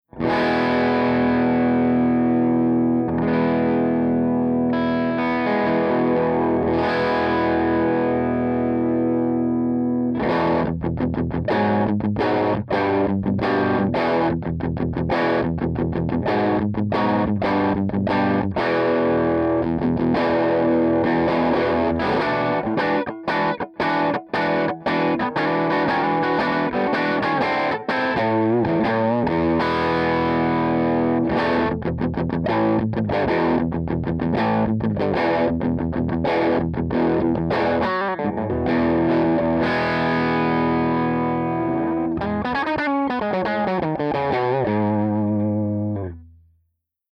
005_AC30_TOPBOOST_P90